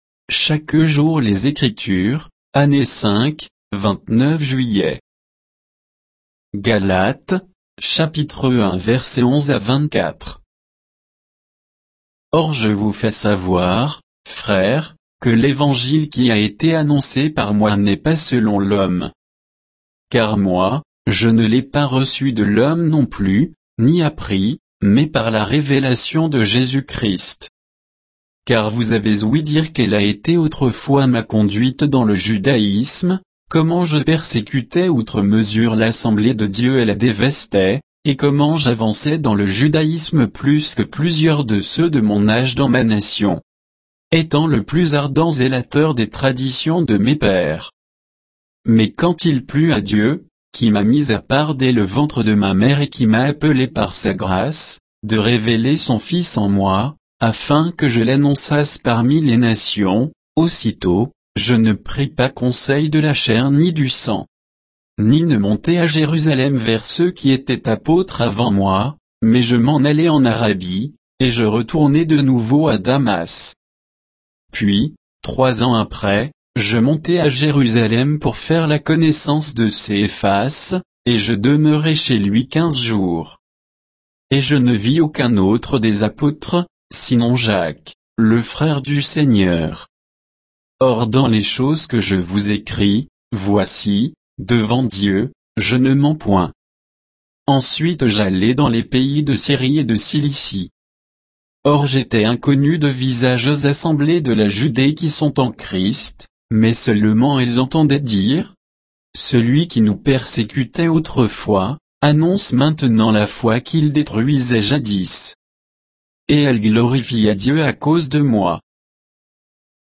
Méditation quoditienne de Chaque jour les Écritures sur Galates 1